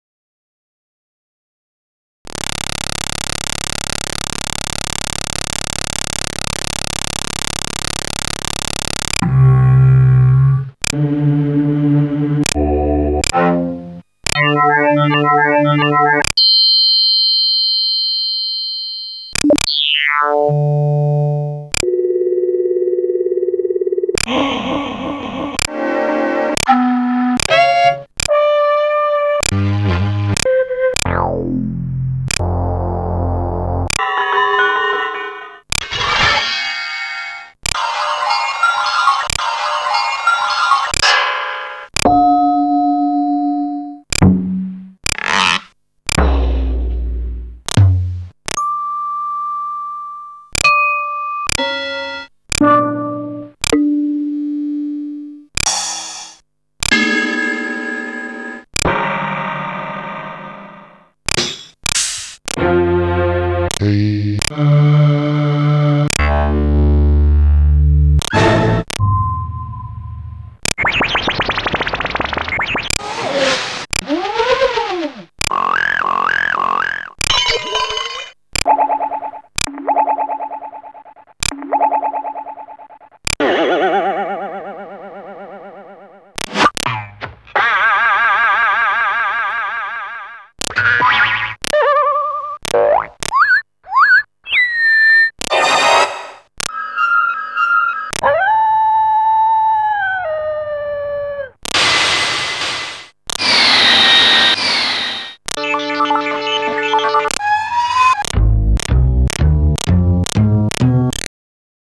FairilightIISamples (to chop).wav